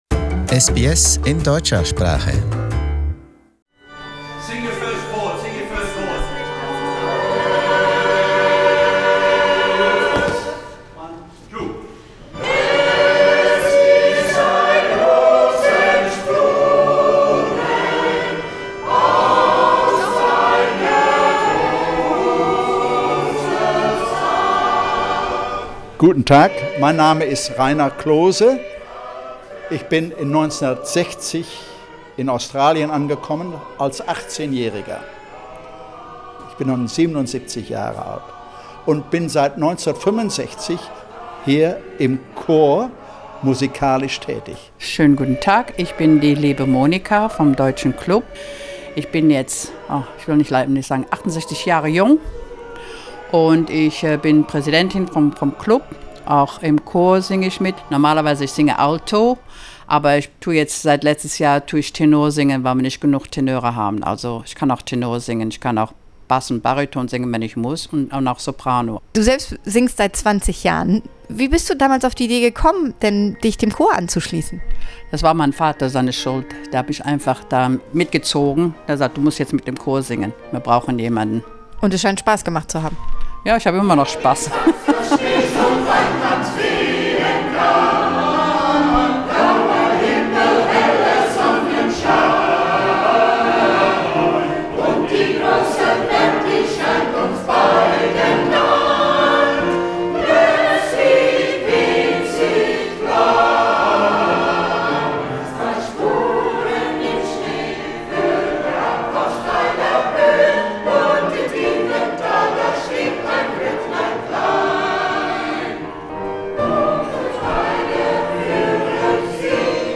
The “Liederkranz/ Liedertafel” choir rehearses once a week in the German Club in East Brisbane.